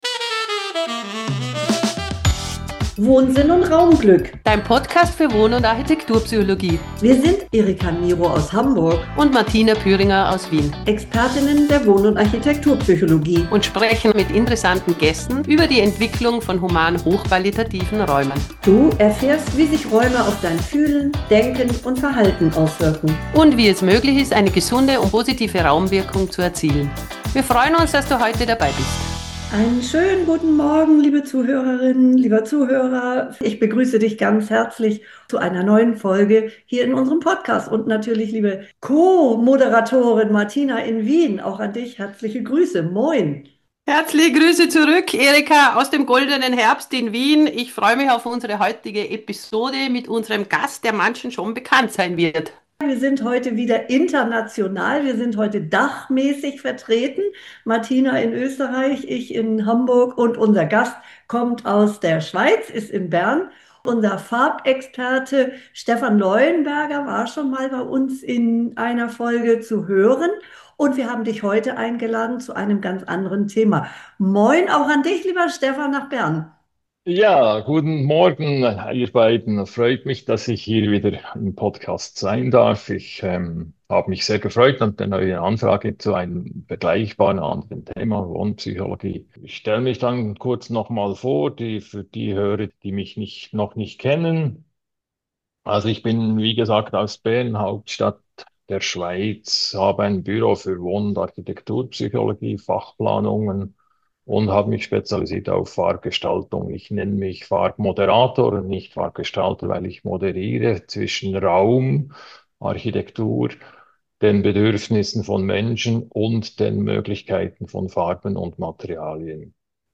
Ein inspirierendes Gespräch über gemeinschaftliches Wohnen, wohnpsychologische Perspektiven und den Mut, neue Wege zu gehen.